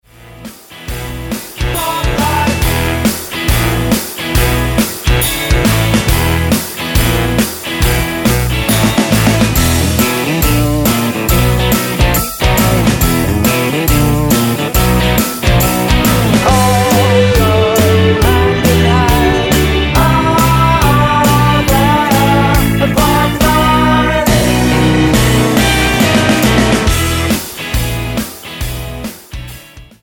Tonart:E mit Chor